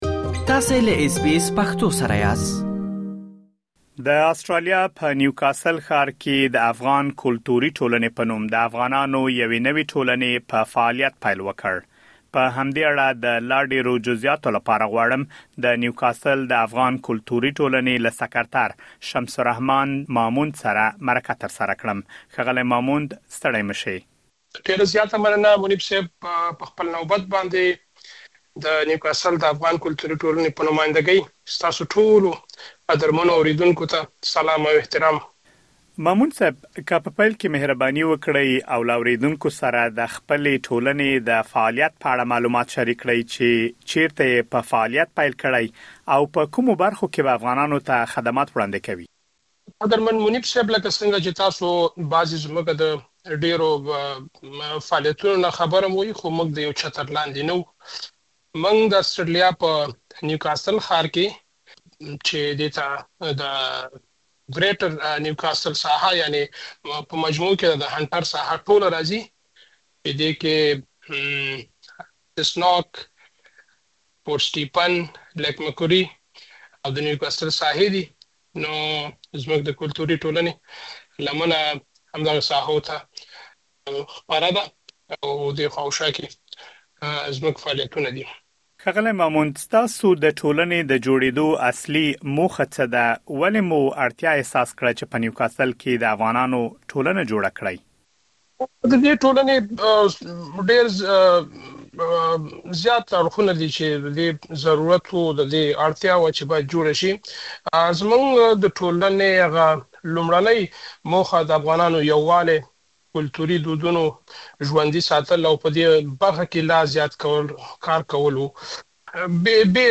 تاسو کولی شئ مهم معلومات په ترسره شوې مرکې کې واورئ.